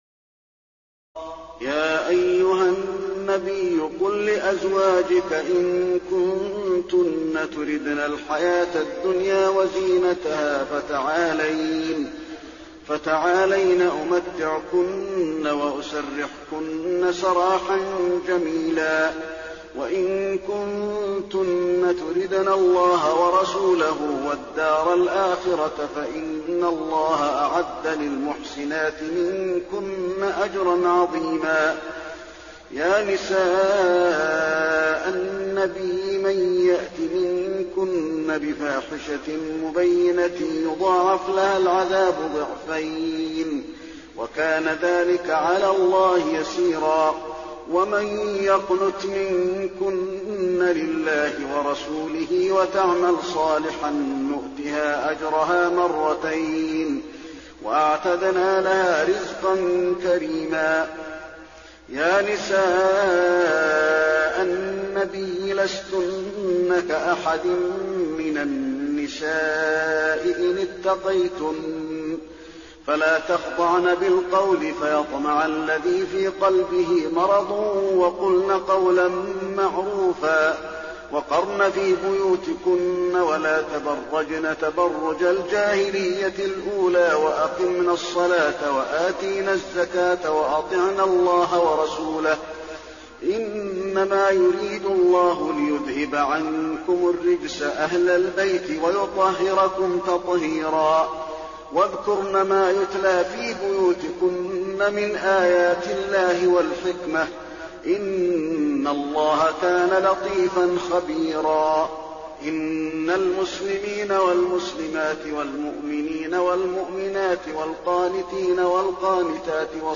تراويح ليلة 21 رمضان 1422هـ من سور الأحزاب (28-73) و سبأ (1-23) Taraweeh 21 st night Ramadan 1422H from Surah Al-Ahzaab and Saba > تراويح الحرم النبوي عام 1422 🕌 > التراويح - تلاوات الحرمين